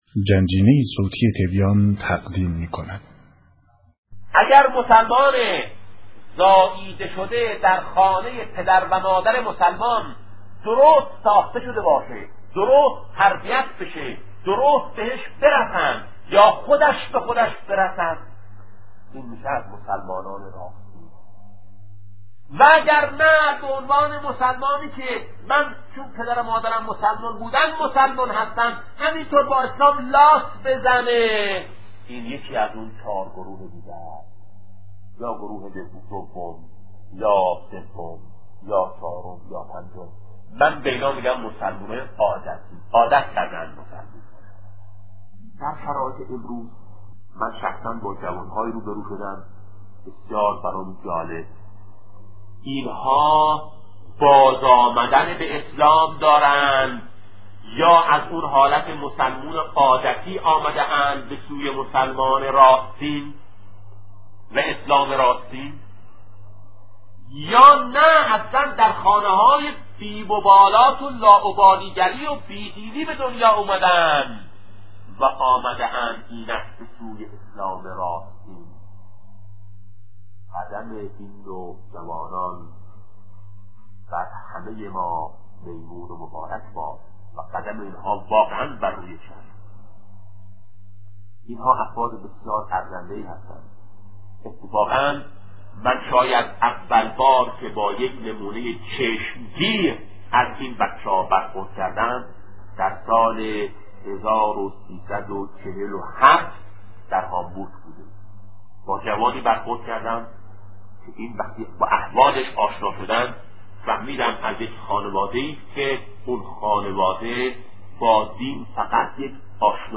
سخنرانی شهید بهشتی(ره)- سه گونه اسلام-بخش‌دوم